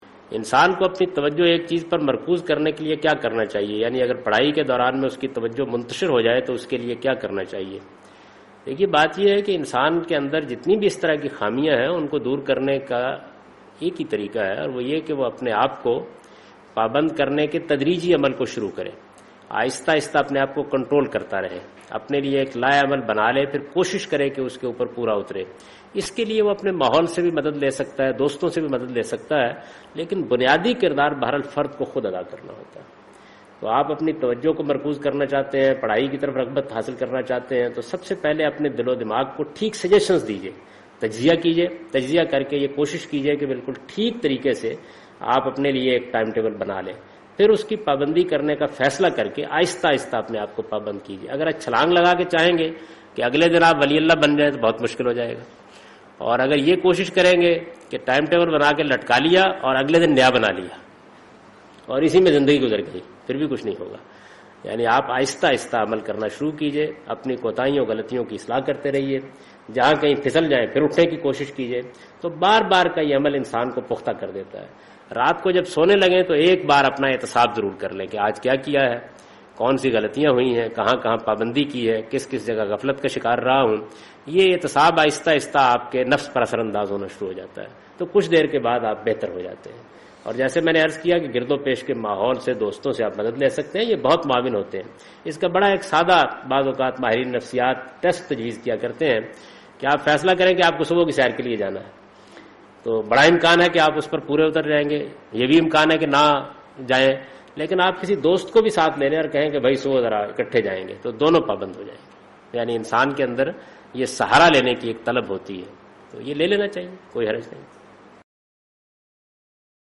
Javed Ahmad Ghamidi responds to the question 'How to improve concentration'?